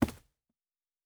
Footstep Carpet Running 1_03.wav